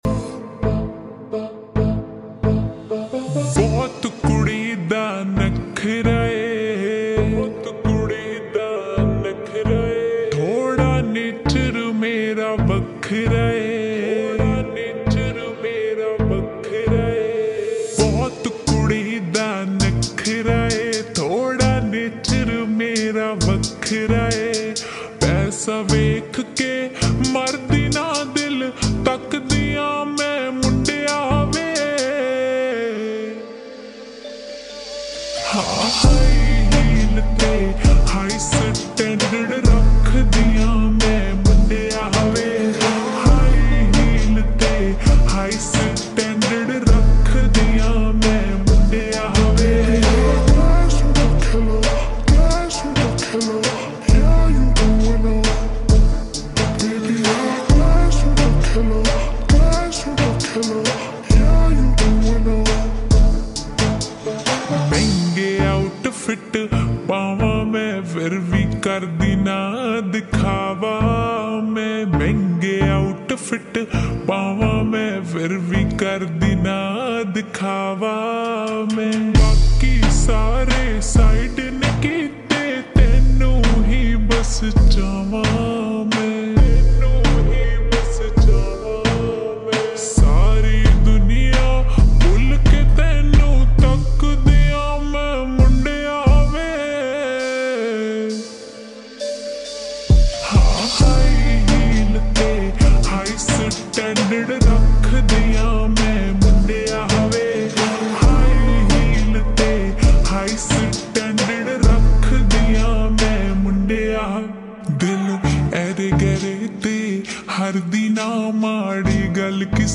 (HIGH STANDARD) Use Headphone Slow And Reverb